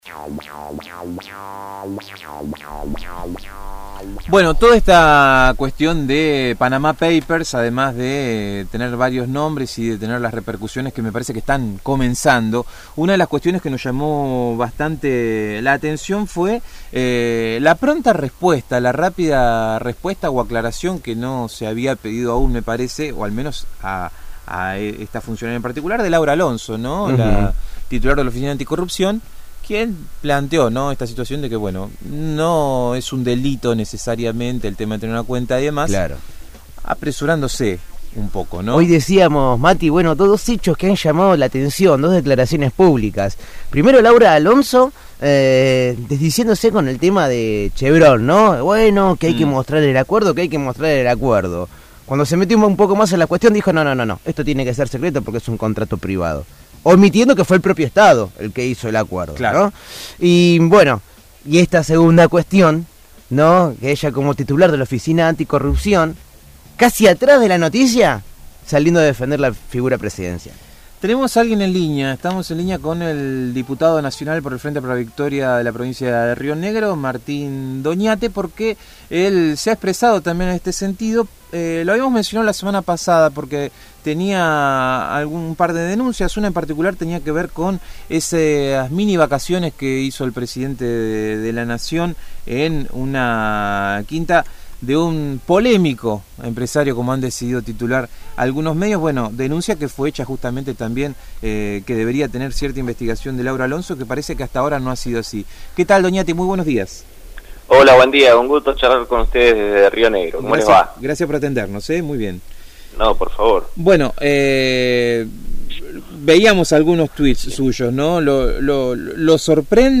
Martín Doñate, diputado nacional del Frente Para la Victoria por la provincia de Río Negro